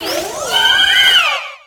Cri de Florges dans Pokémon X et Y.